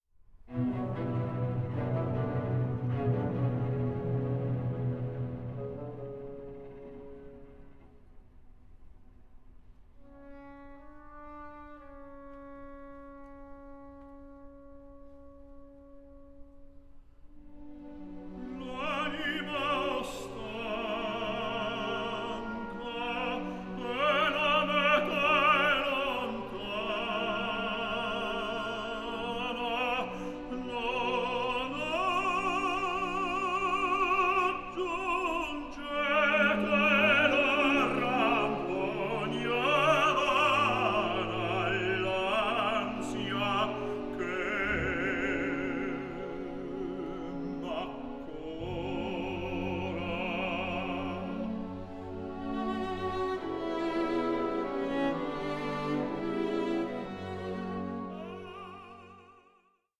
HEART-WRENCHING VERISMO ARIAS